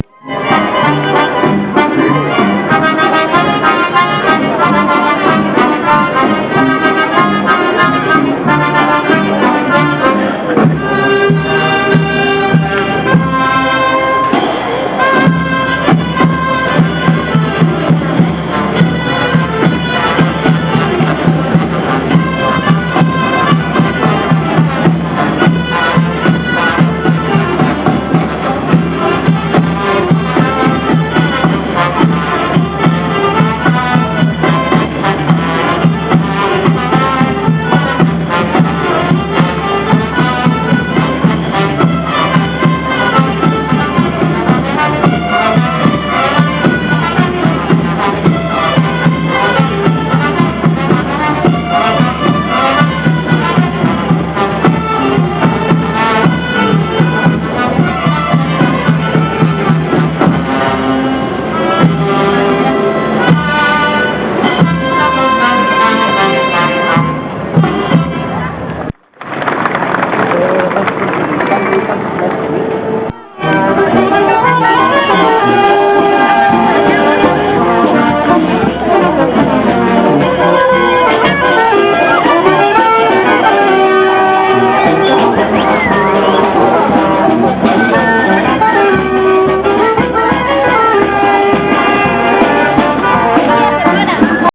Each of these were originally recorded on my trusty Psion...
Festival time in Oaxaca, Mexico (2037K)